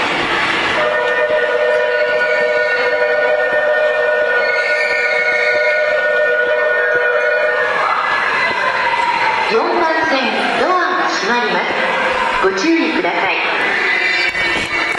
ベル 東急を思い出す…